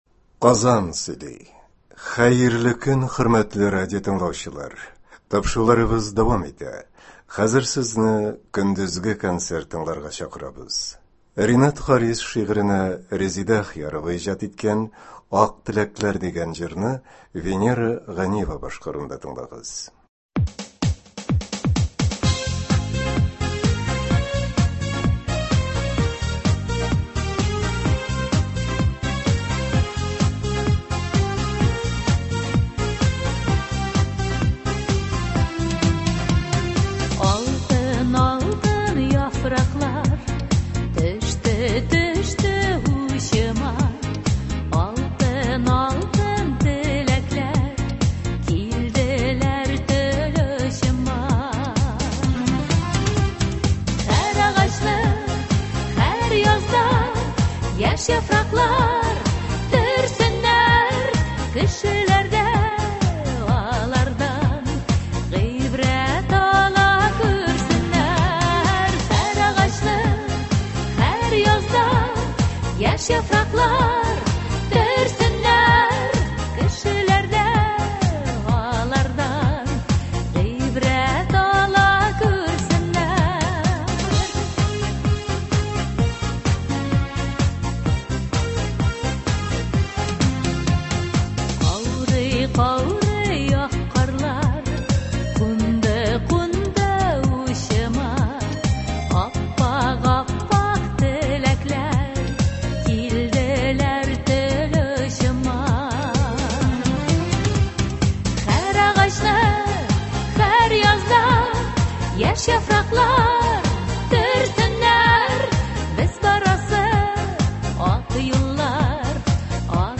Драма артистлары концерты.